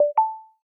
Charger_Connection.ogg